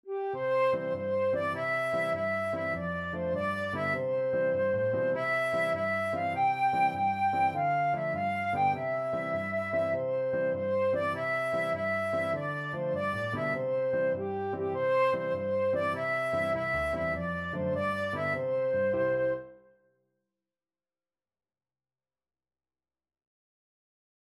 Traditional Trad. Itsy Bitsy Spider (English Traditional) Flute version
6/8 (View more 6/8 Music)
G5-G6
Quick two in a bar . = c.100
C major (Sounding Pitch) (View more C major Music for Flute )
Traditional (View more Traditional Flute Music)